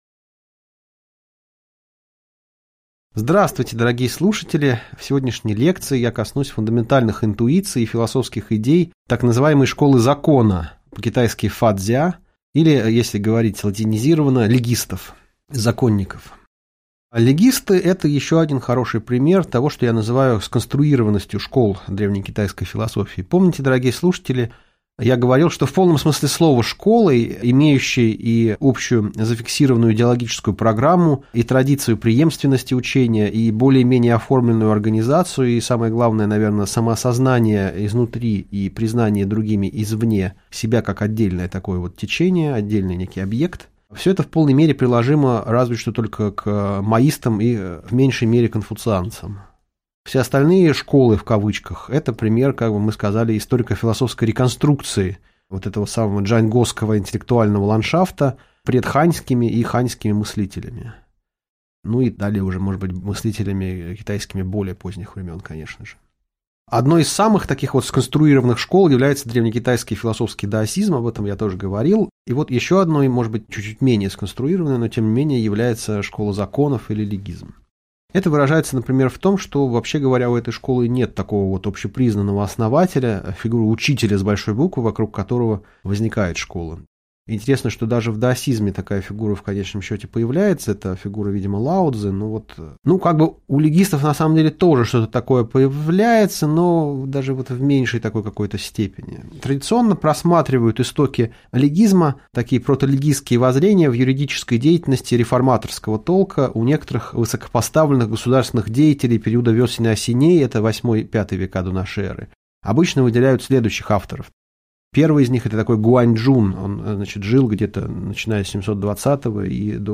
Аудиокнига Лекция «Легизм» | Библиотека аудиокниг